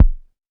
TR 808 Kick 02.wav